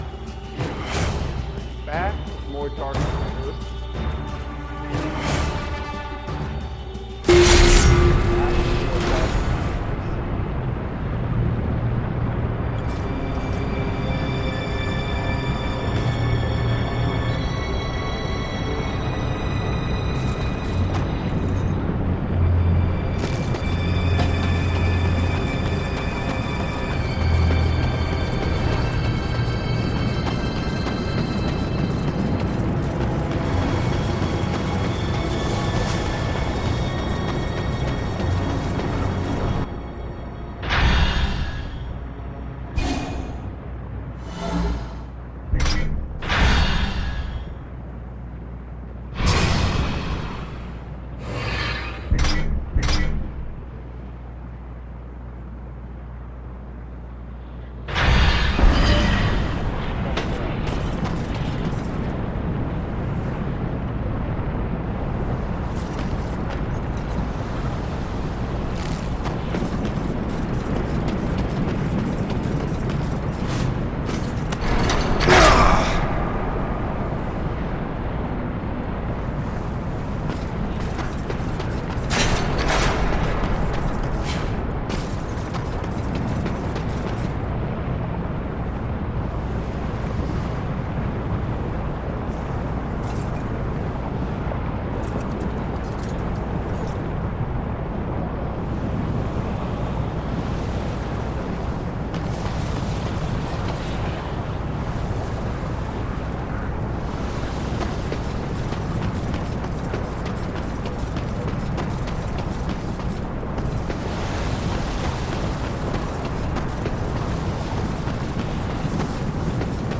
I play Darksiders with commentary